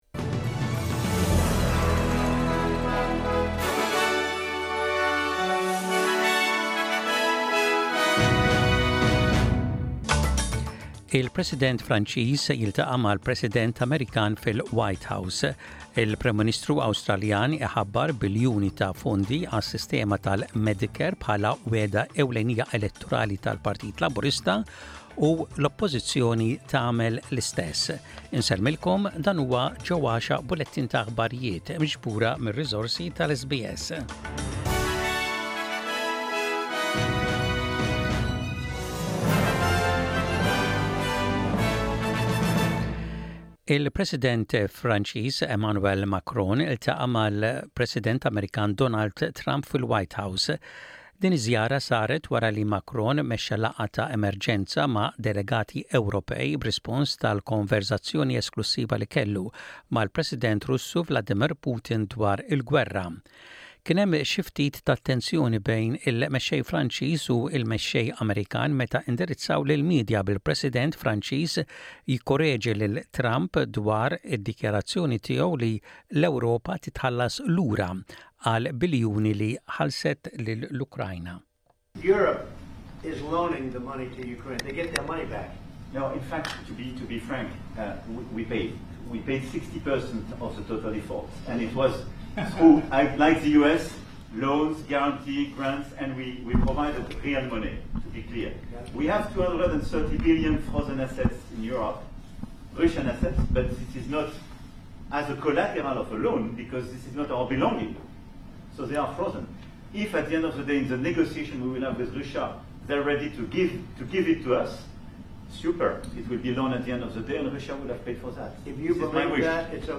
Aħbarijiet bil-Malti: 25.02.25